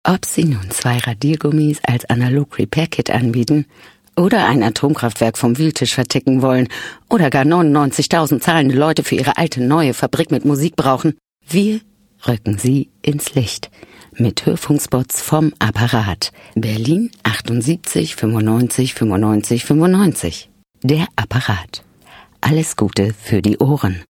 • Rundfunk-/Werbespots